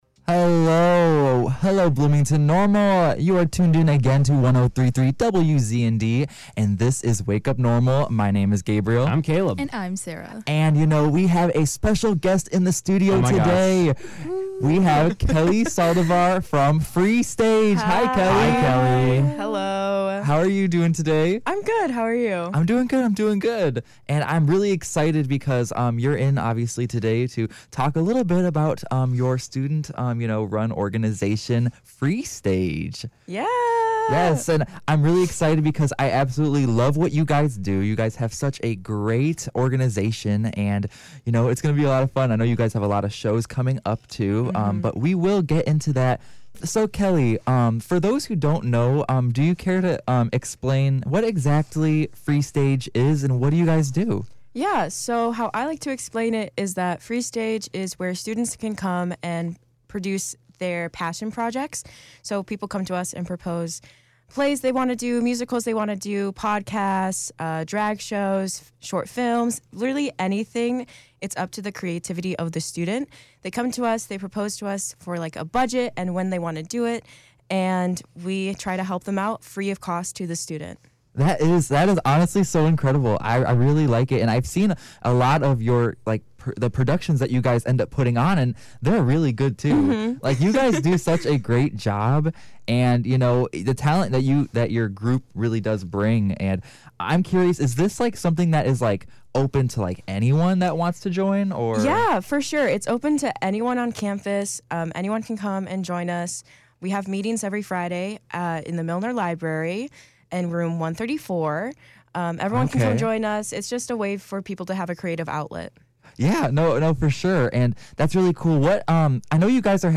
FreeStage-Interview-2.1.24.mp3